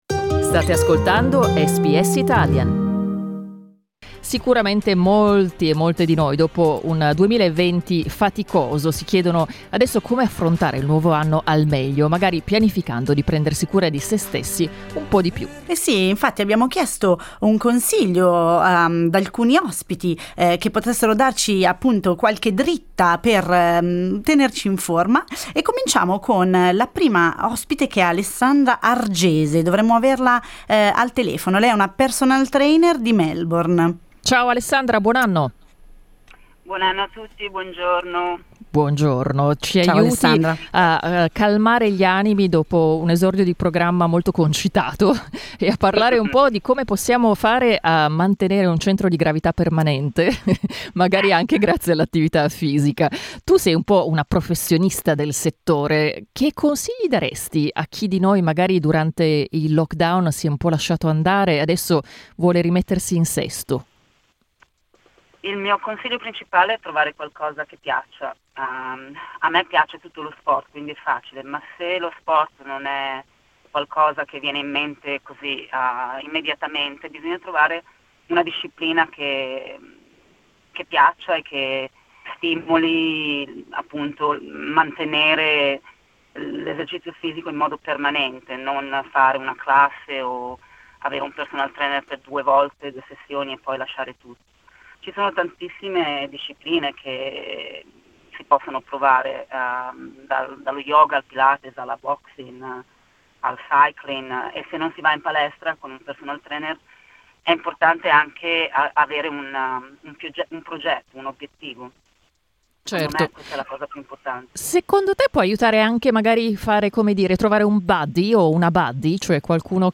Sono arrivate diverse telefonate cariche di buoni propositi e consigli, che potrebbero essere utili per tanti.